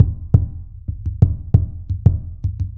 BON86DUNDUN.wav